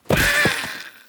enemy_big_defeat.ogg